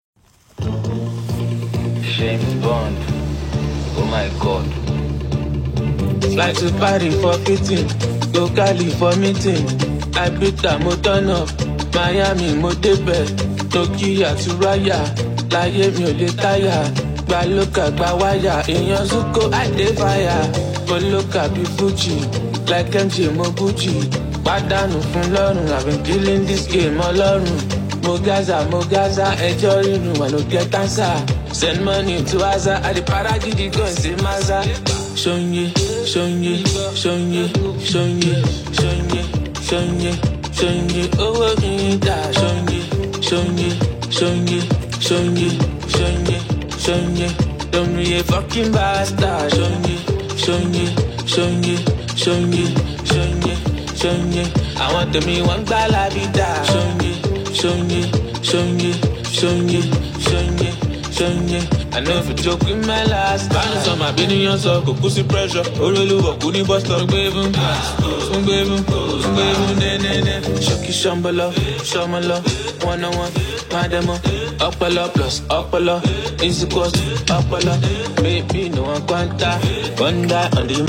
Nigerian rap
catchy lyrics, infectious energy, and an unforgettable hook